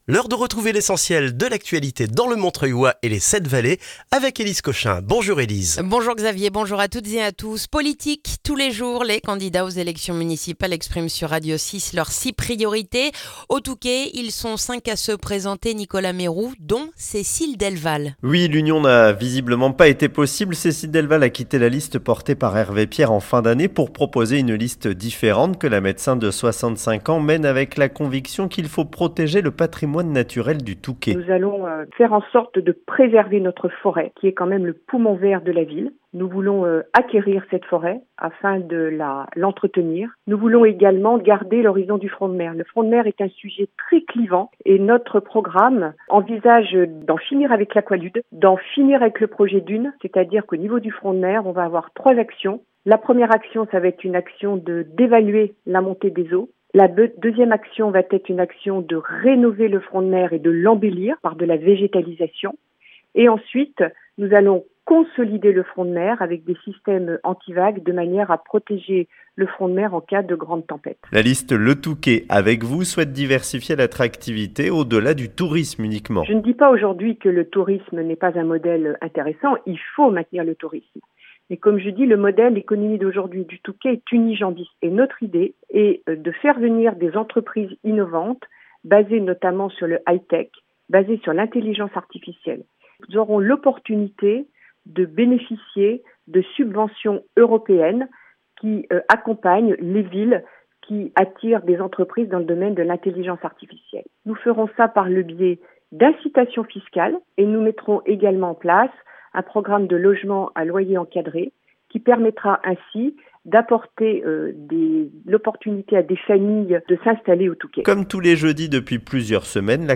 Le journal du mardi 10 mars dans le montreuillois